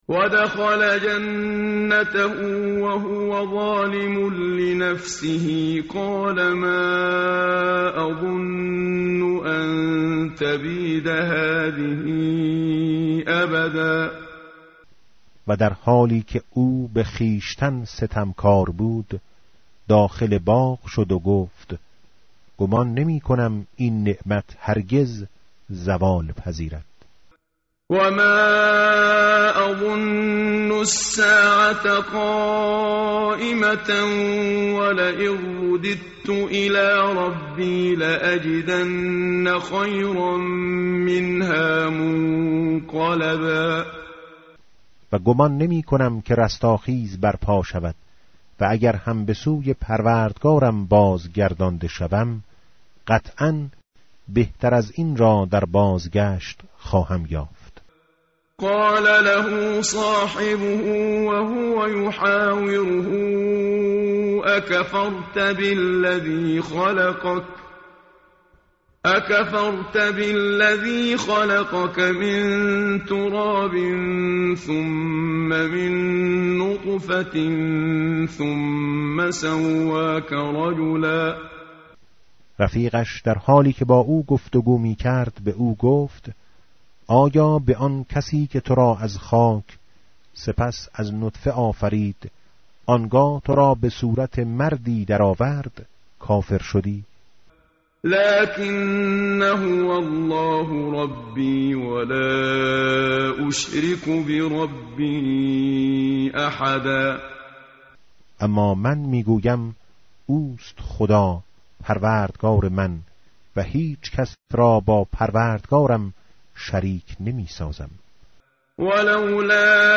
متن قرآن همراه باتلاوت قرآن و ترجمه
tartil_menshavi va tarjome_Page_298.mp3